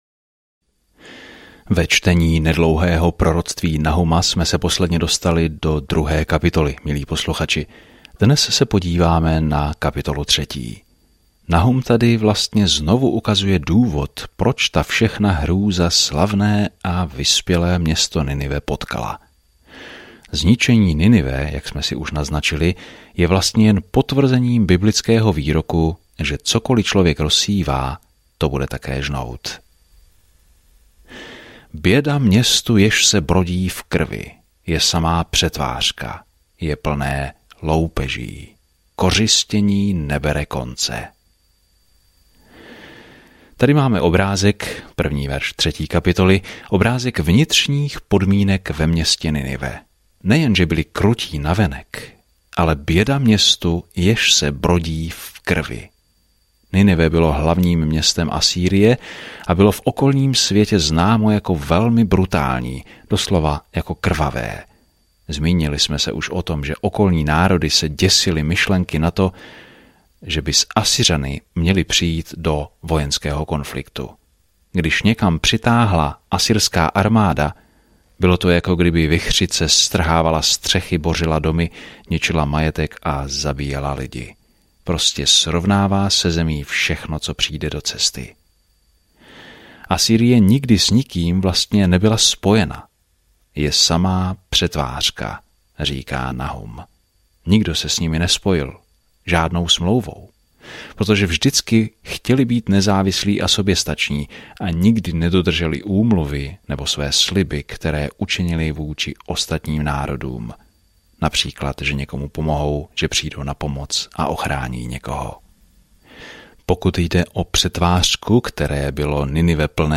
Písmo Nahum 3:1-5 Den 3 Začít tento plán Den 5 O tomto plánu Nahum, jehož jméno znamená útěchu, přináší Božím nepřátelům poselství soudu a přináší Izraeli spravedlnost i naději. Denně procházejte Nahum a poslouchejte audiostudii a čtěte vybrané verše z Božího slova.